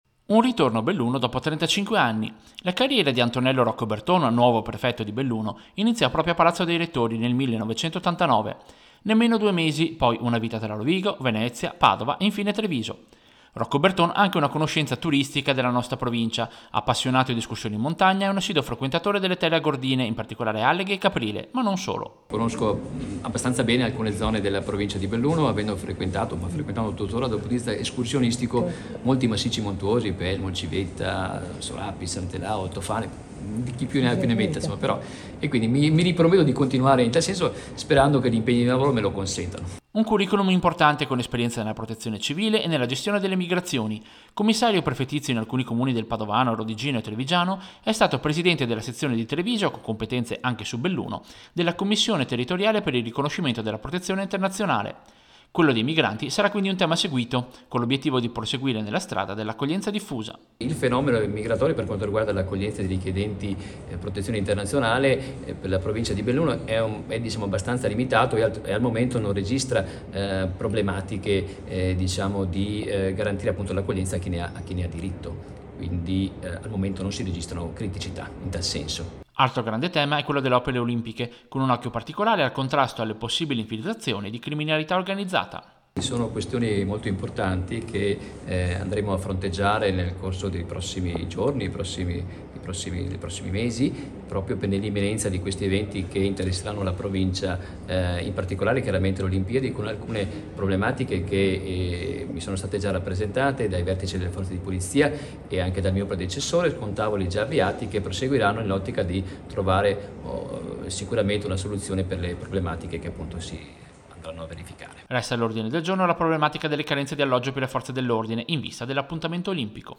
BELLUNO Antonello Roccoberton, nuovo prefetto di Belluno, ha incontrato la stampa per analizzare le priorità della provincia.
Servizio-Nuovo-prefetto-Roccoberton.mp3